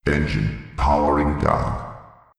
poweroff.wav